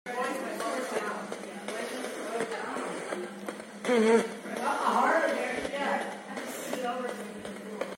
ITC: Unexplained Voice says Hey sound effects free download